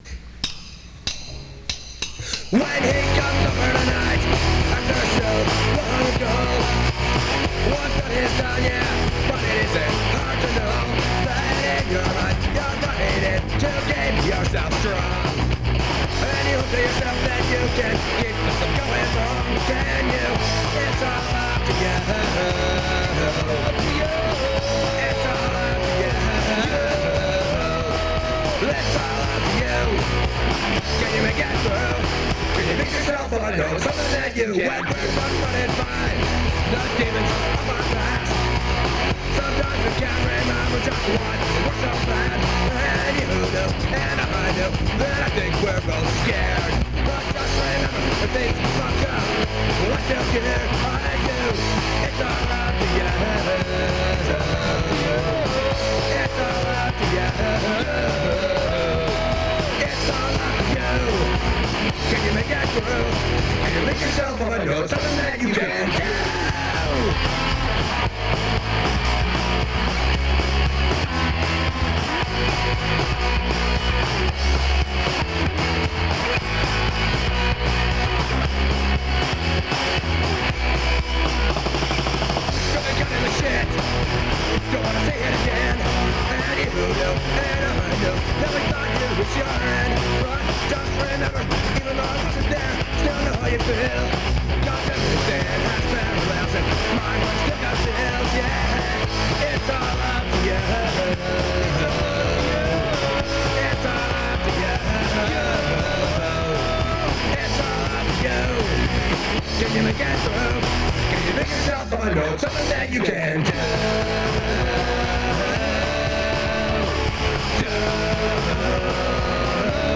Guitar/Vocals
Drums
Bass/Backing Vocals
Pop-Punk